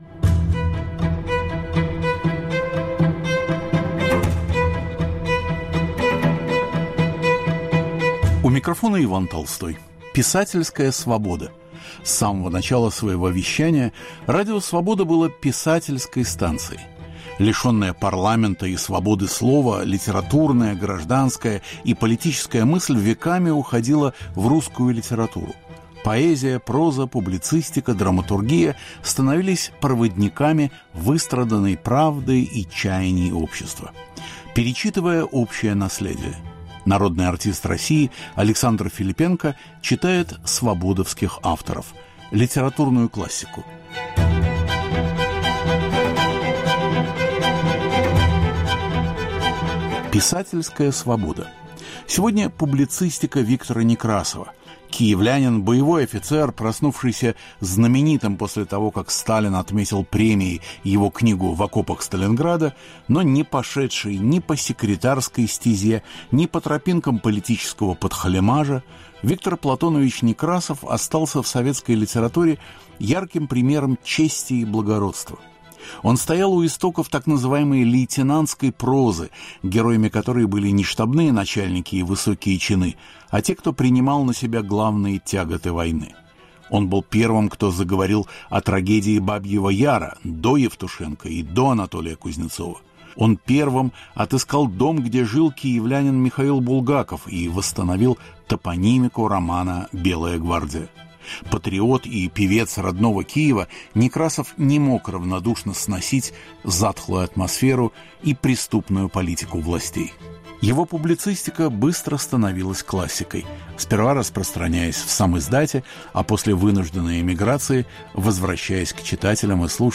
В Цикле "Писательская Свобода" народный артист Александр Филиппенко читает статьи Виктора Некрасова киевского периода.